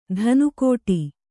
♪ dhanu kōṭi